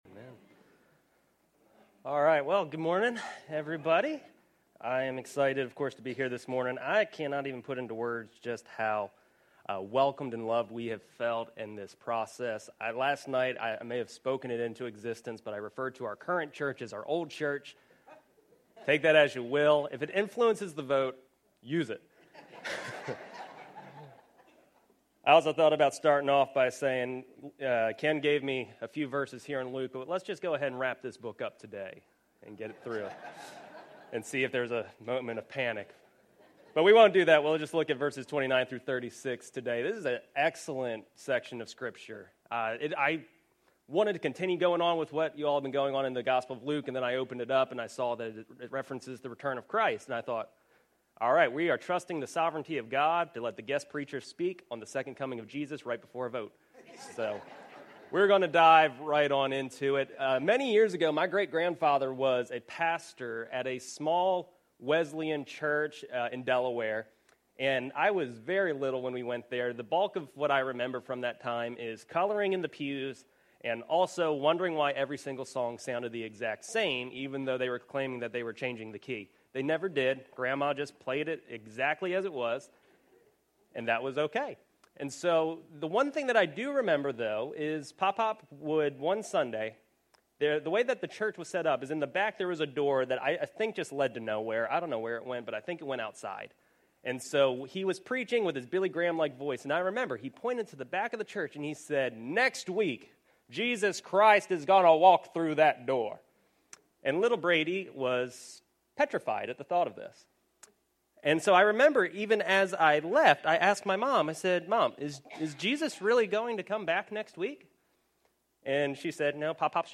Sermons | Crossroads Church